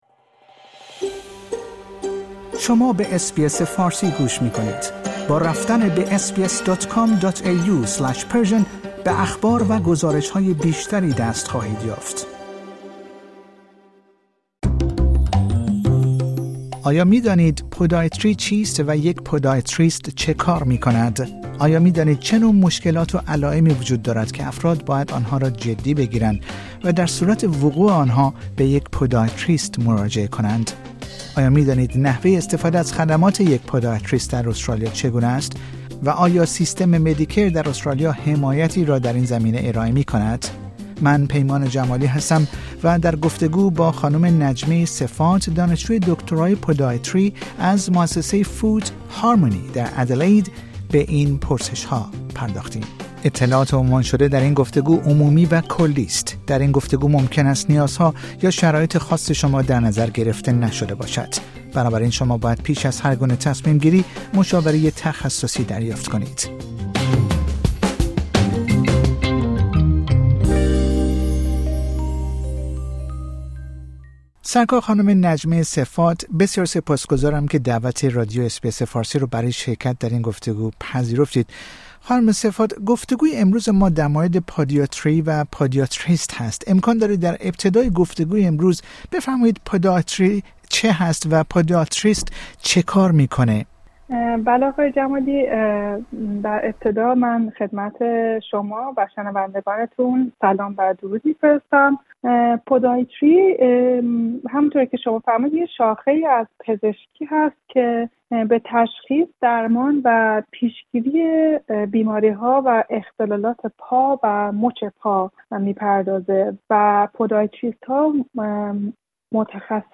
در این گفتگو ممکن است نیازها یا شرایط خاص شما در نظر گرفته نشده باشد.